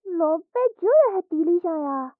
三楼/囚室/肉铺配音偷听效果处理；